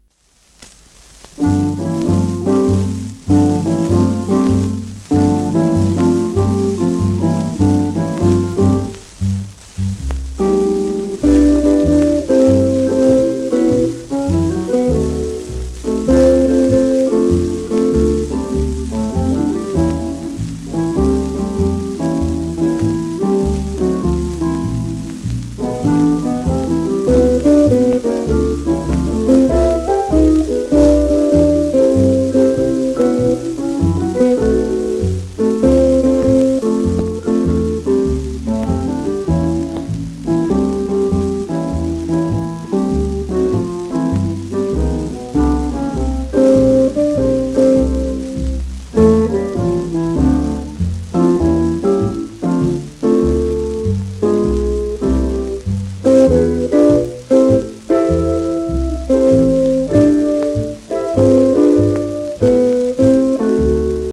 セクステット
盤質B+ *サーフェイスノイズ